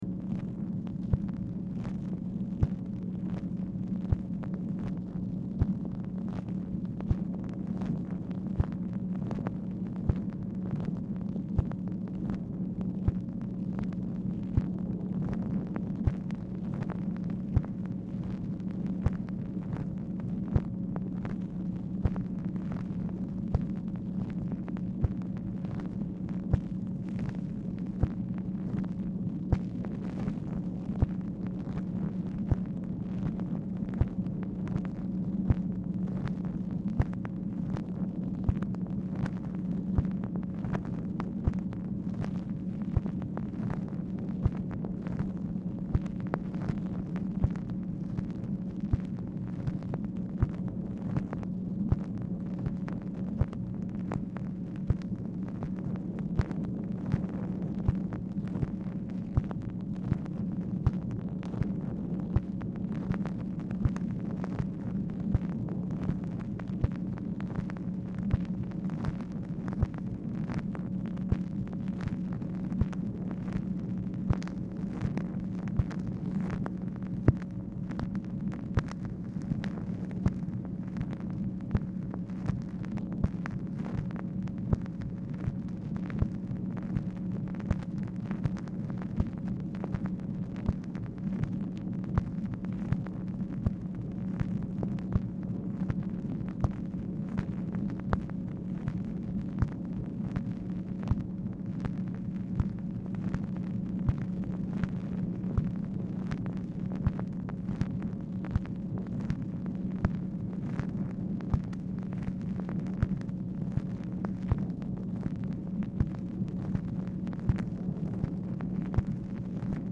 Telephone conversation # 13522, sound recording, MACHINE NOISE, 10/7/1968, time unknown | Discover LBJ
Dictation belt
Oval Office or unknown location